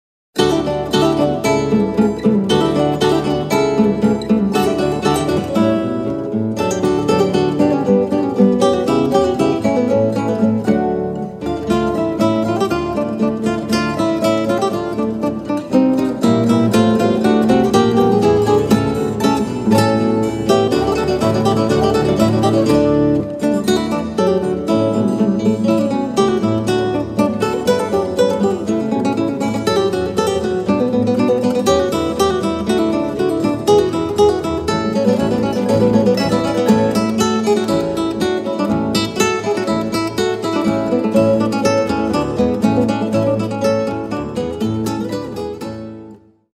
KONZERT FÜR LAUTE IN D-DUR – antonio vivaldi